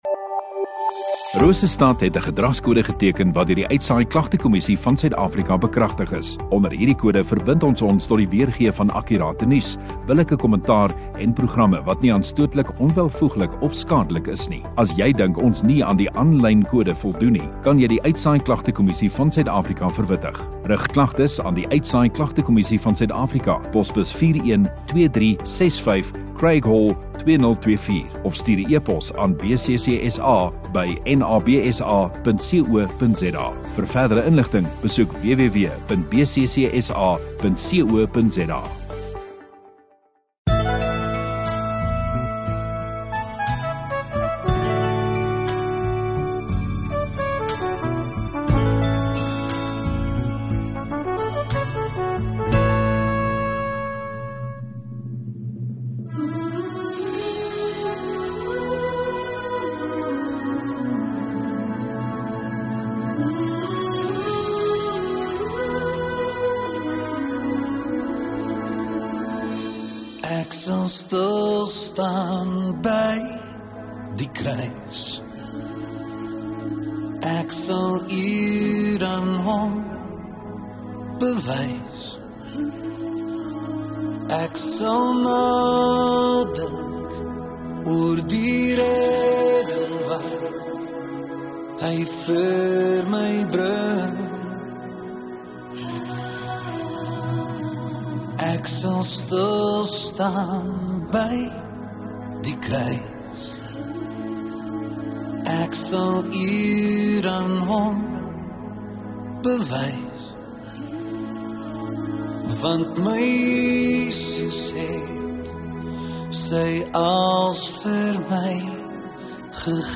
4 Aug Sondagoggend Erediens